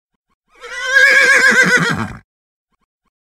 Pferdewiehern Klingelton
Klingelton Pferdegalopp Pferdegeräusch Wiehern
Holen Sie sich den kostenlosen Klingelton mit dem typischen Wiehern eines Pferdes, das in seiner Box ruft.
pferdewiehern-klingelton-de-www_tiengdong_com.mp3